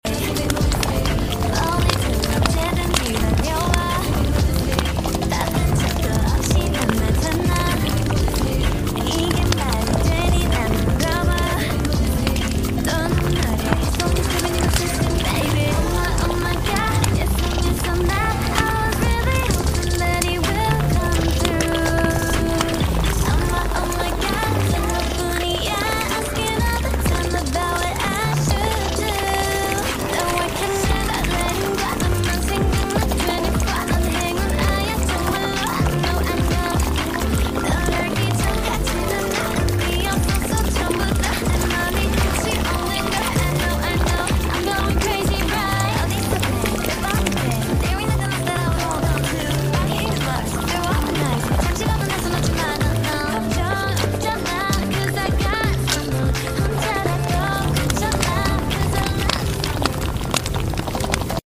Cafe Sounds To Use: — Sound Effects Free Download